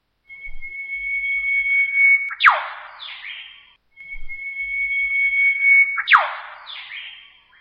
Ik had hem al eerder gehoord, 15 jaar geleden, bij O'Reilly's. Ook een fantastisch geluid. Hier was het wat zachter.
whipbird.mp3